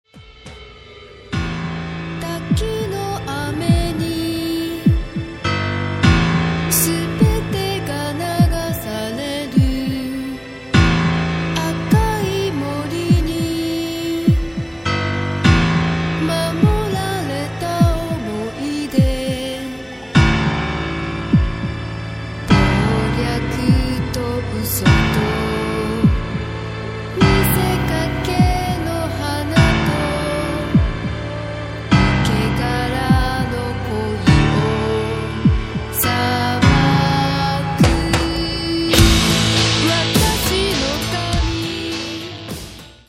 Cyberpunk + Industrial + Goth + Deathrock